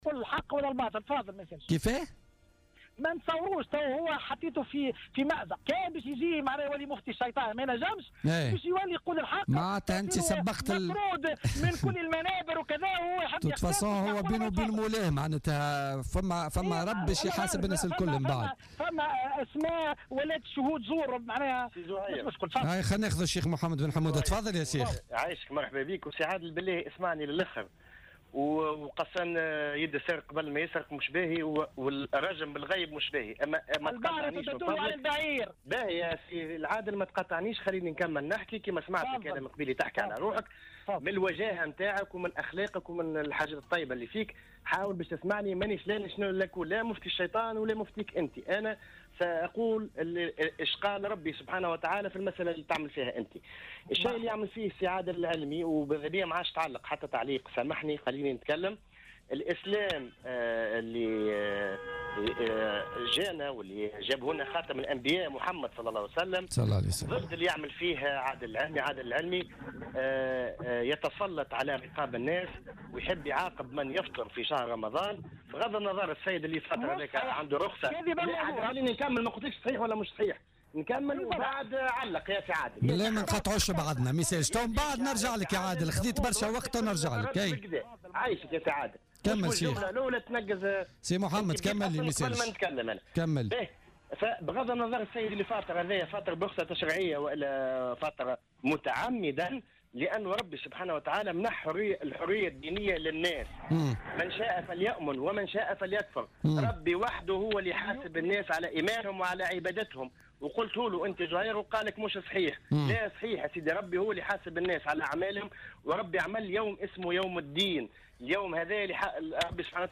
مشادة كلامية